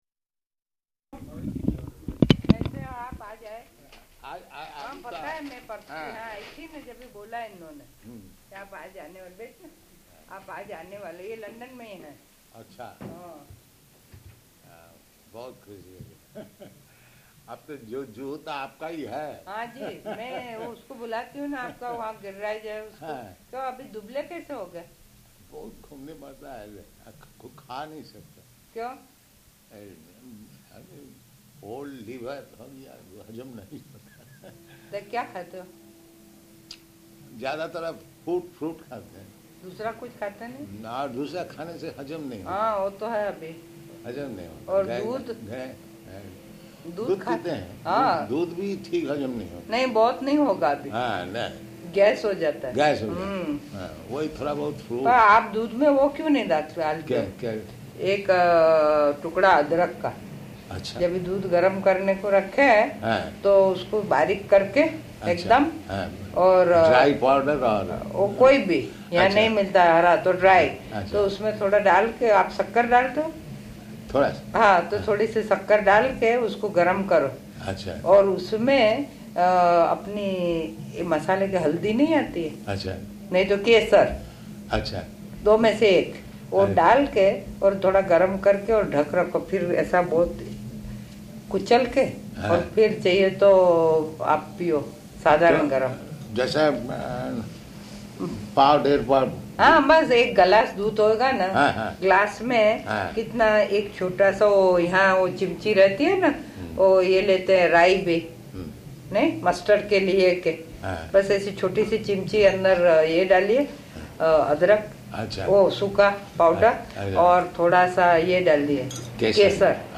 Type: Conversation
Location: London